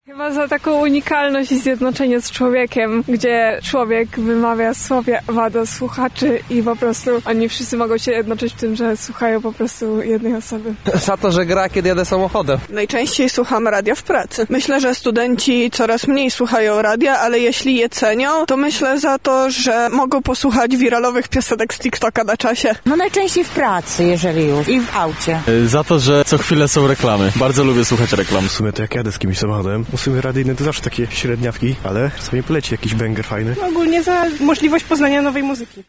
Zapytaliśmy mieszkańców Lublina o to, jakie znaczenie ma dla nich radio:
sonda